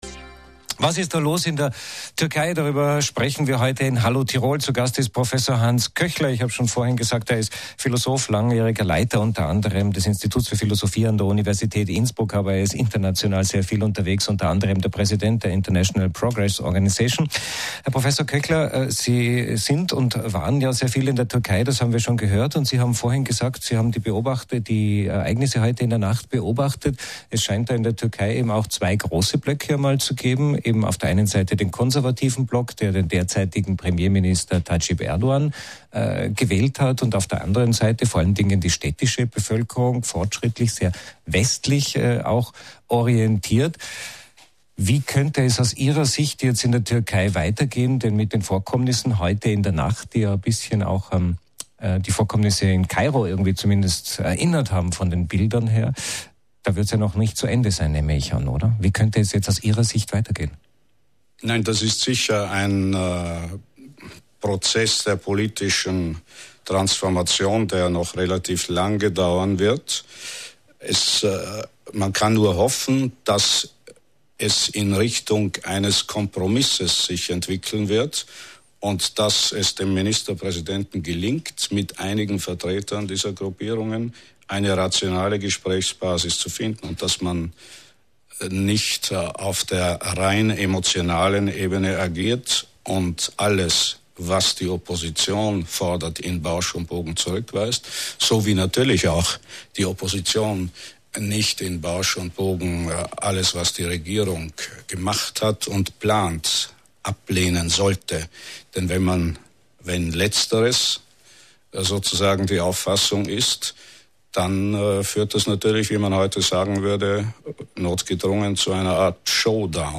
"Brennpunkt Naher Osten" -- Gespr�ch